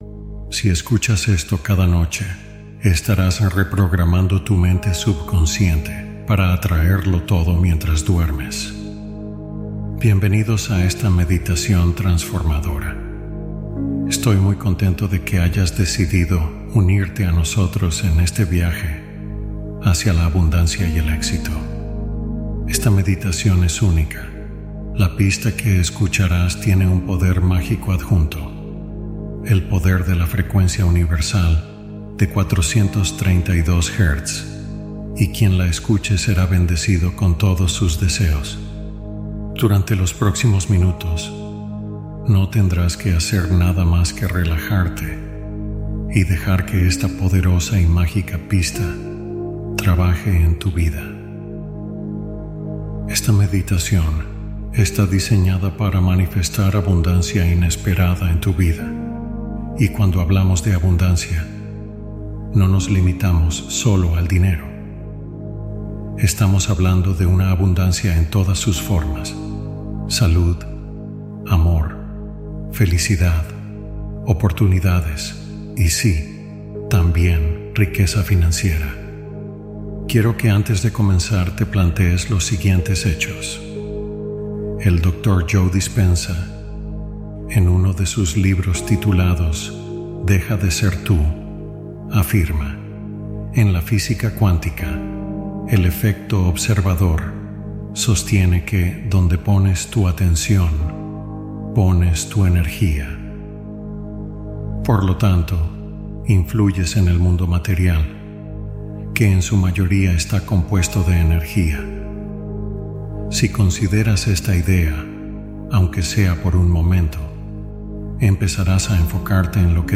Abundancia como intención: reprogramación nocturna en 432Hz